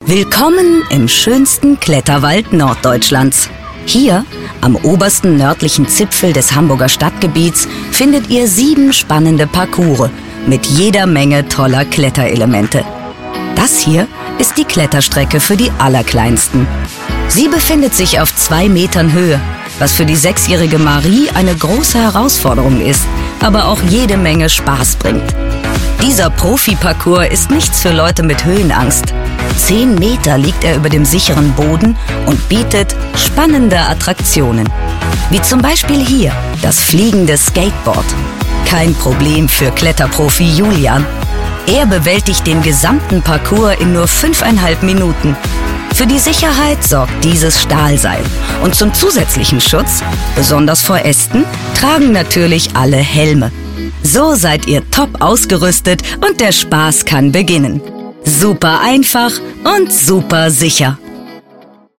Wandelbare, charakteristische Stimme mit einem Stimmalter von ca 30 bis 50 Jahren.
Sprechprobe: Industrie (Muttersprache):
Imagefilm Kletterwald.mp3